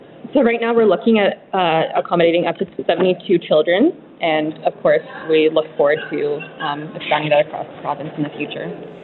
Bramfield adds that the Child Care Centre facility can accommodate 72 children, with future goals to create more Child Care Facilities across the province.